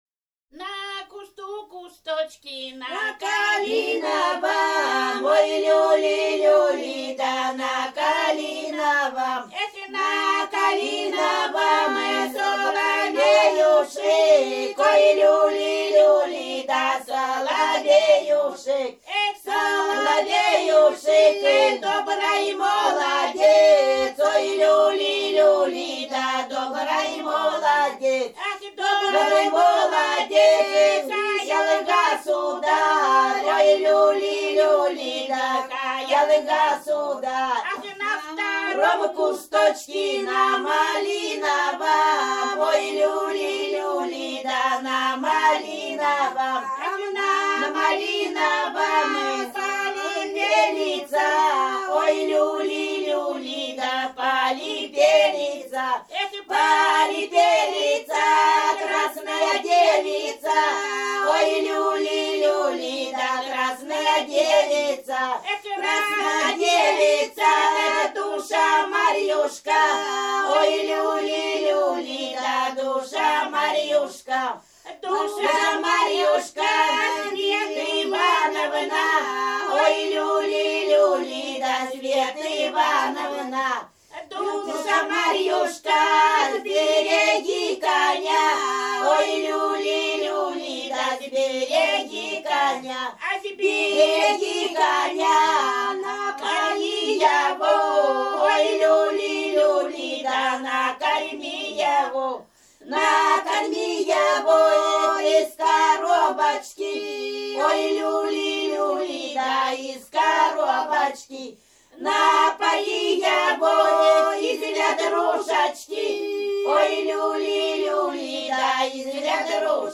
Рязань Кутуково «На кусту кусточке», свадебная плясовая.